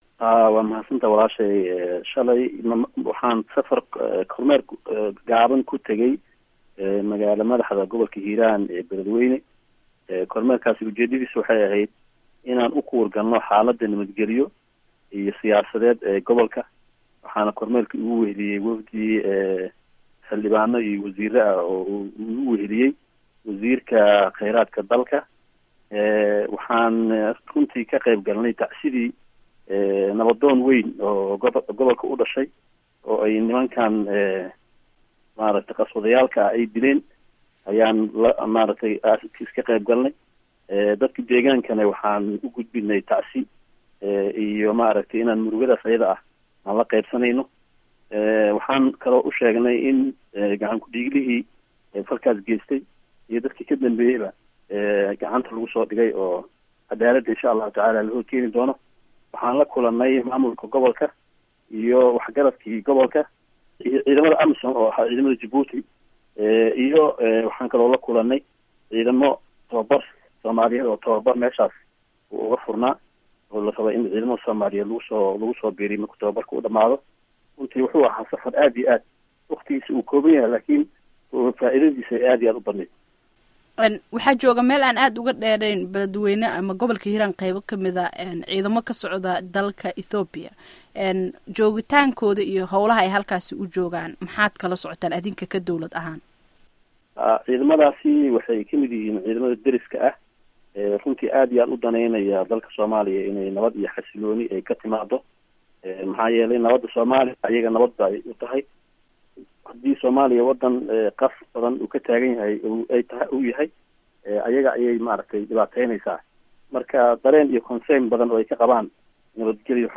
Wareysiga Wasiirka Arrimaha Gudaha